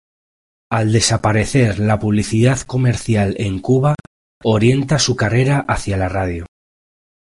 Masculino
co‧mer‧cial
/komeɾˈθjal/